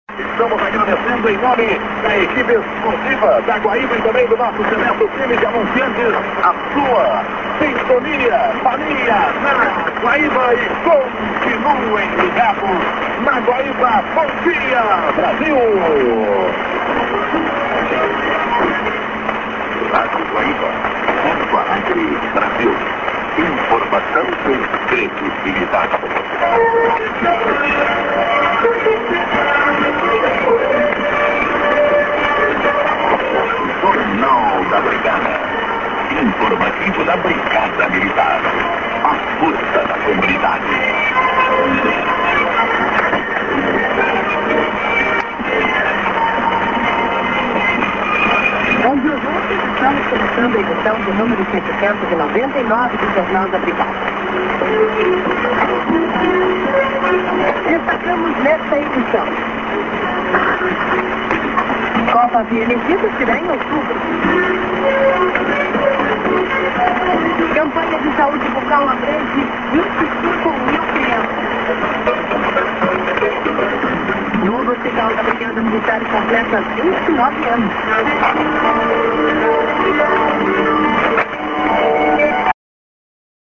->ID(man)->music->ANN(women)->